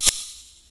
attackimpact1.ogg